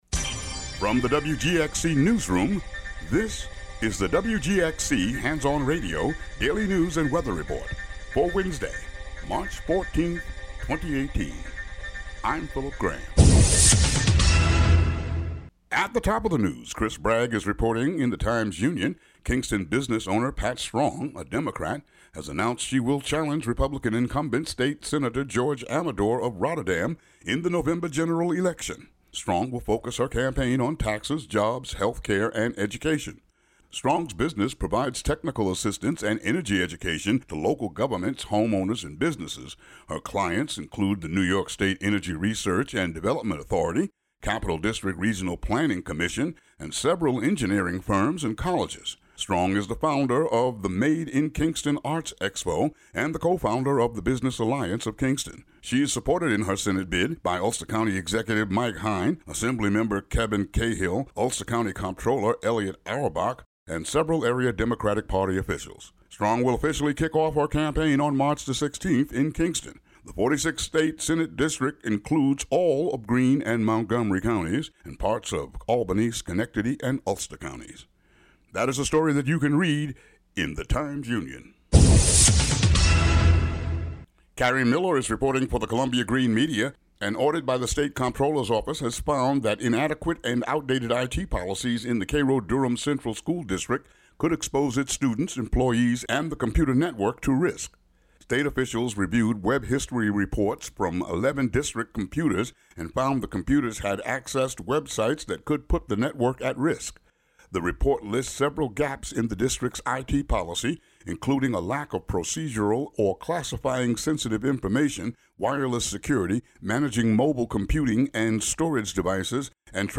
The local news for the WGXC listening area.